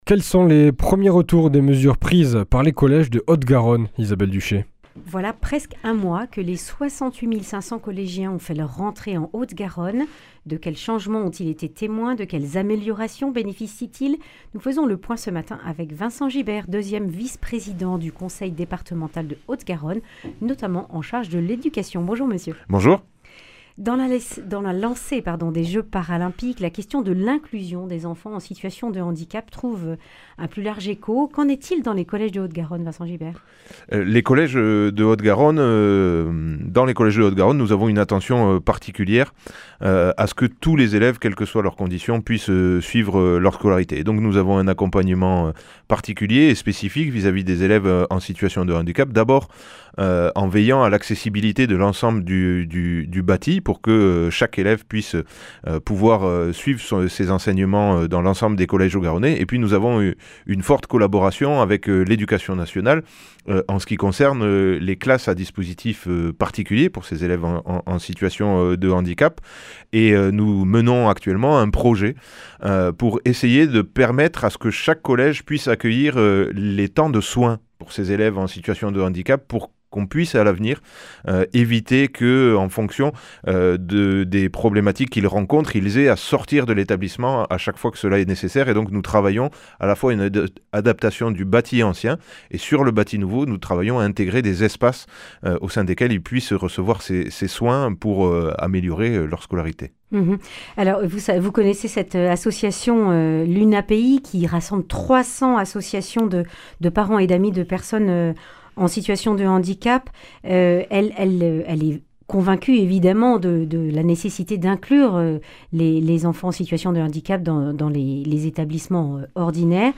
Eléments de réponse avec Vincent Gibert, deuxième vice-président du conseil départemental de Haute-Garonne, en charge de l’éducation.
Accueil \ Emissions \ Information \ Régionale \ Le grand entretien \ Un mois après la rentrée des collégiens, quels changements ?